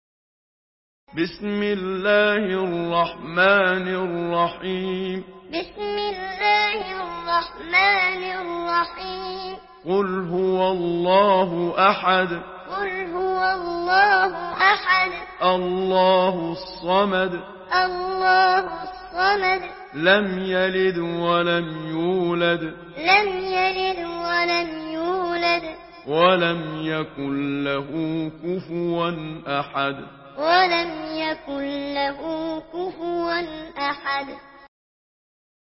Surah الإخلاص MP3 by محمد صديق المنشاوي معلم in حفص عن عاصم narration.